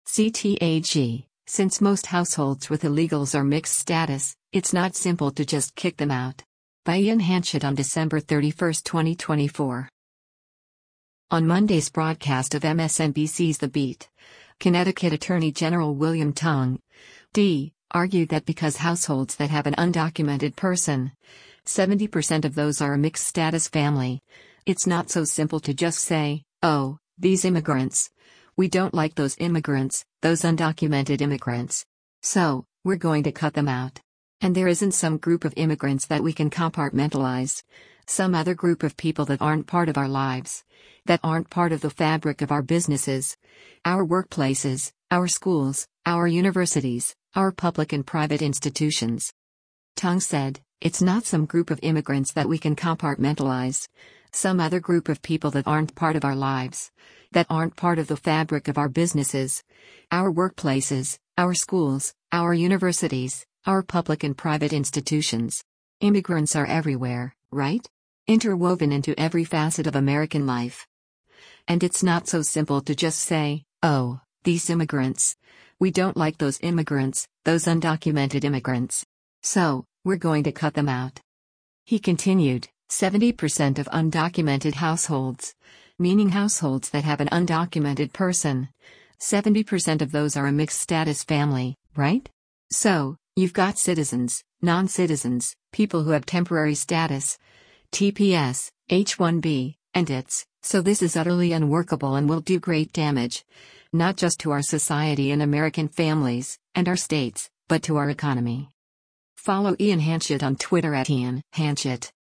On Monday’s broadcast of MSNBC’s “The Beat,” Connecticut Attorney General William Tong (D) argued that because “households that have an undocumented person, 70% of those are a mixed-status family,” “it’s not so simple to just say, oh, these immigrants, we don’t like those immigrants, those undocumented immigrants. So, we’re going to cut them out.”